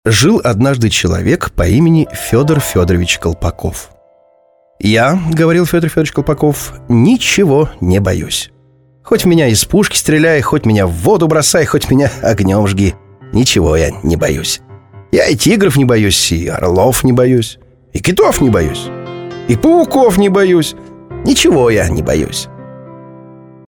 Классическая подача,игровые.
Audio-Technica AT 4050 TL-Audio Dual Valve Mic Preamp/DI Digidesign Digi 001